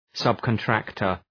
Shkrimi fonetik{,sʌbkən’træktər}